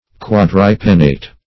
Search Result for " quadripennate" : The Collaborative International Dictionary of English v.0.48: Quadripennate \Quad`ri*pen"nate\, a. [Quadri- + pennate.]